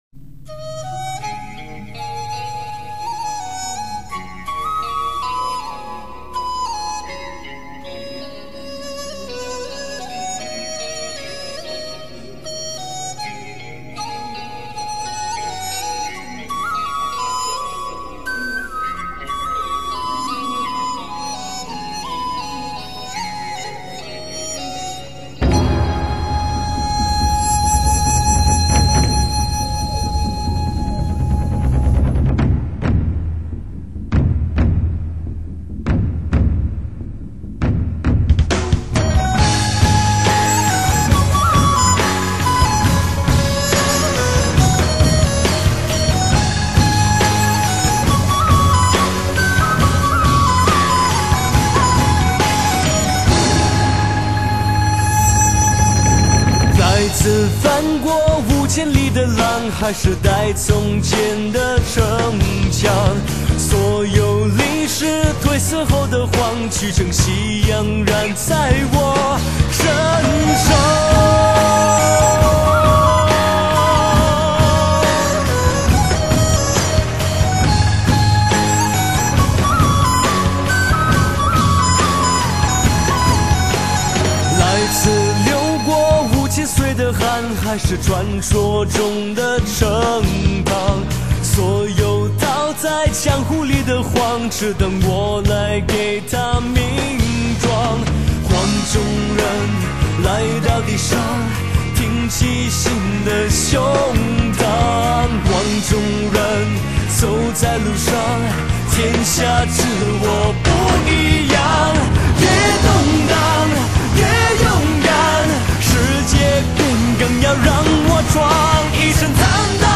节奏很强憾的音乐